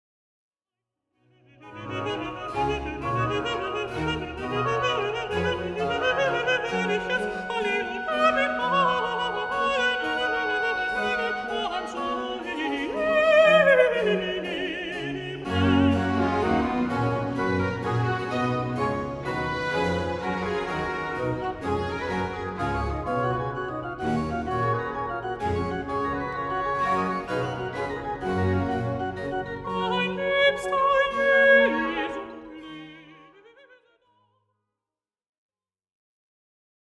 Recitativo Ach, starker Gott, laß mich (+0.99 EUR)